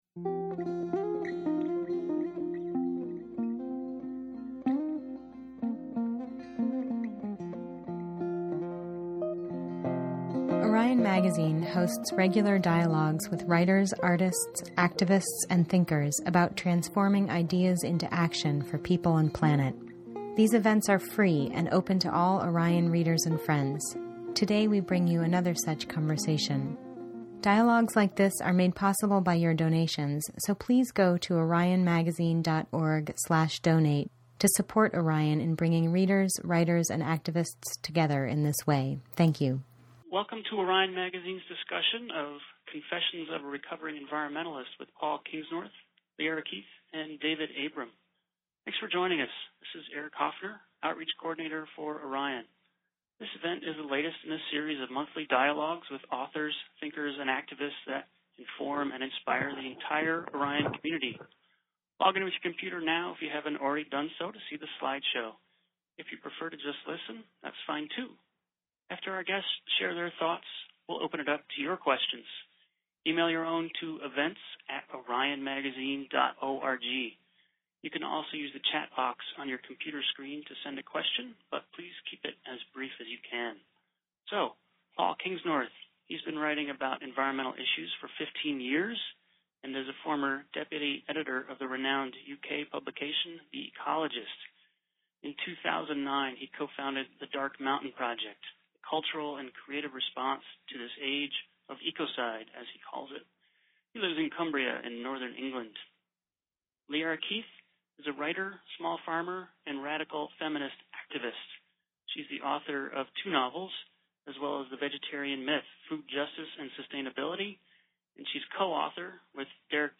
Paul Kingsnorth, Liarre Keith and David Abrams make a very interesting debate about the future of “environmentalism” and what part s